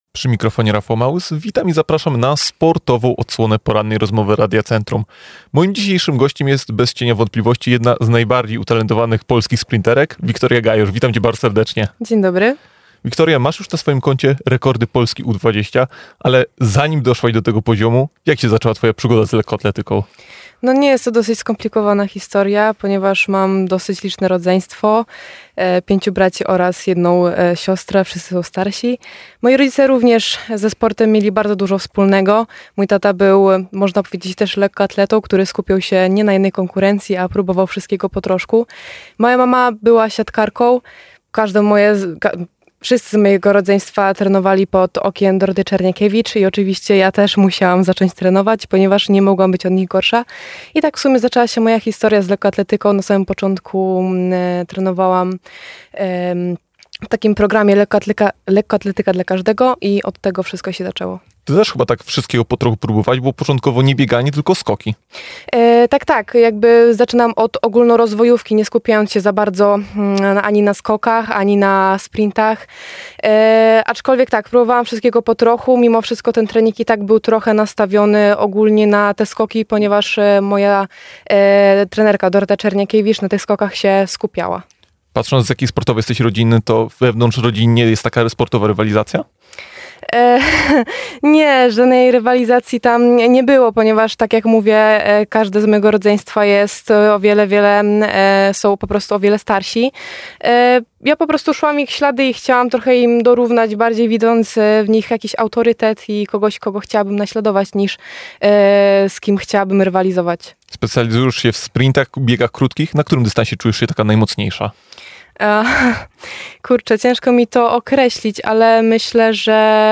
Porozmawialiśmy nie tylko o jej dotychczasowych sukcesach, ale nie brakowało trudnej tematyki oraz kreowania wizerunku w Internecie. Cała rozmowa dostępna poniżej.